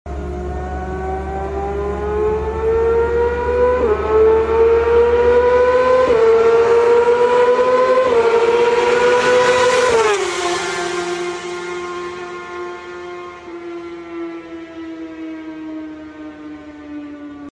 GSXR 600 El Sonido Sound Effects Free Download